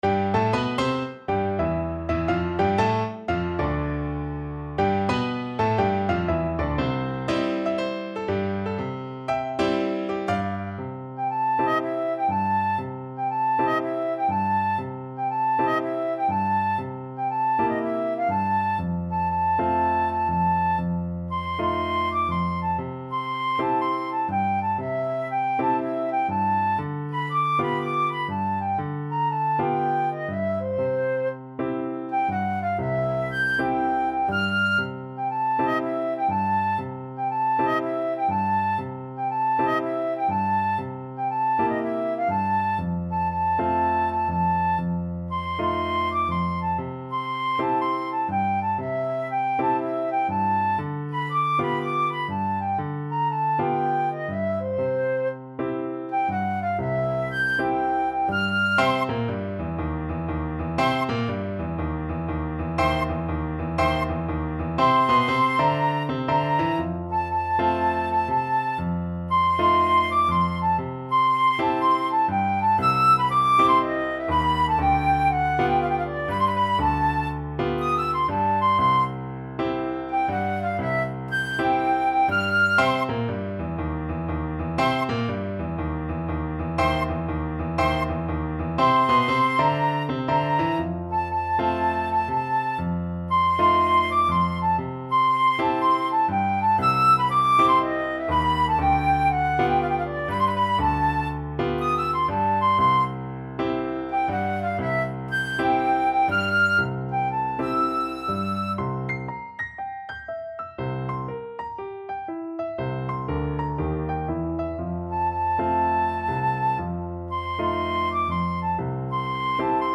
Moderato = 120
4/4 (View more 4/4 Music)
Jazz (View more Jazz Flute Music)